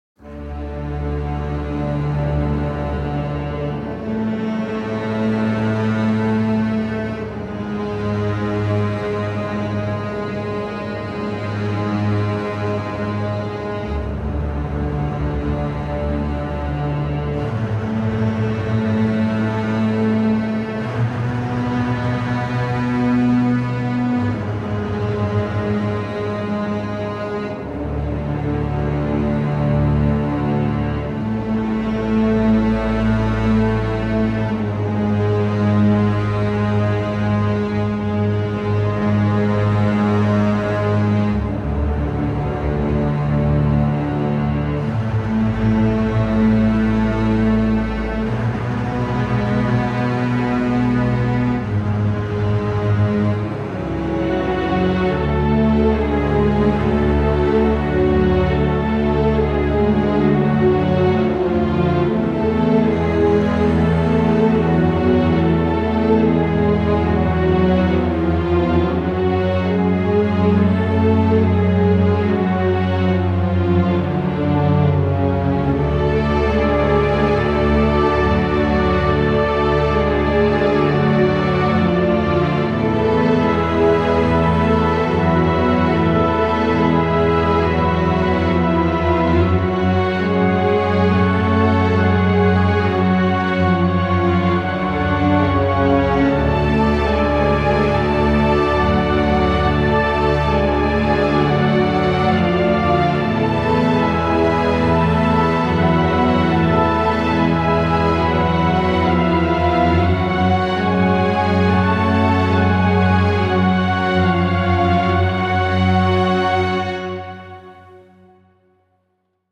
Фоновая музыка для музея Второй Мировой войны